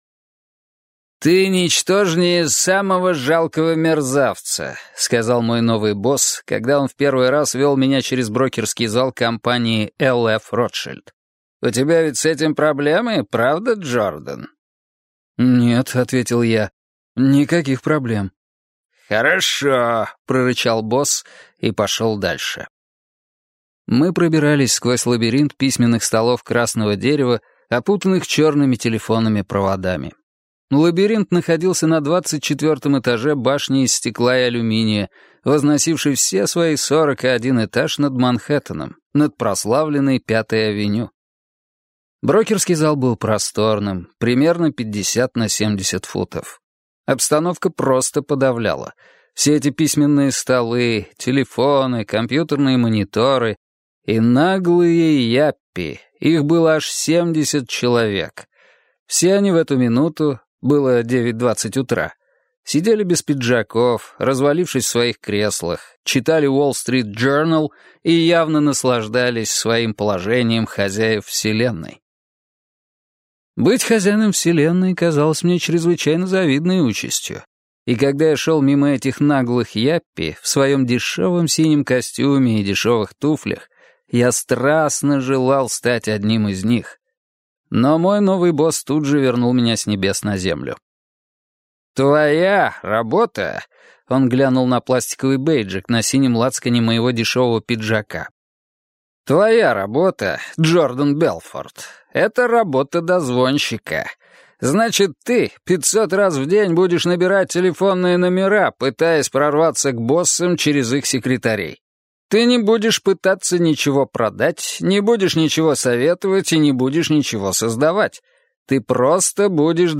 Аудиокнига Волк с Уолл-стрит - купить, скачать и слушать онлайн | КнигоПоиск